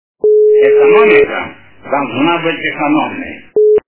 » Звуки » Люди фразы » Брежнев - Экономика должна быть экономной
При прослушивании Брежнев - Экономика должна быть экономной качество понижено и присутствуют гудки.